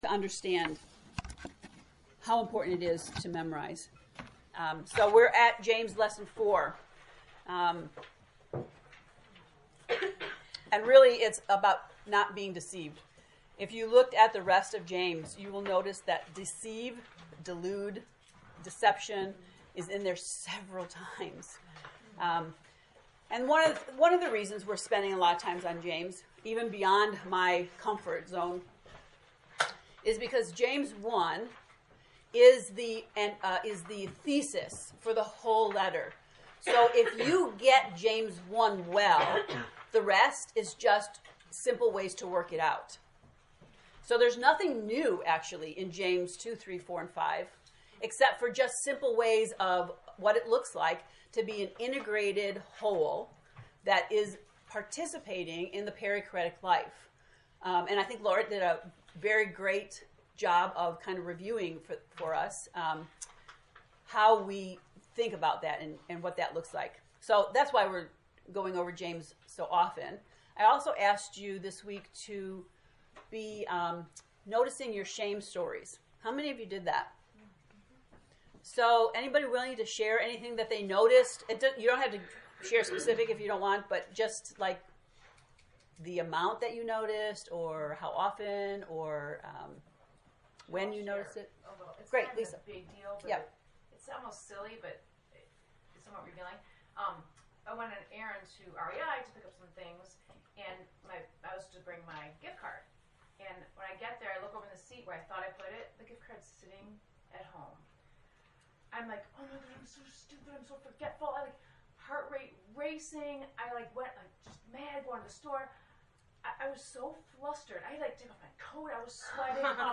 To listen to the lesson 4 lecture, “Don’t Be Deceived,” click below:
james-lect-4.mp3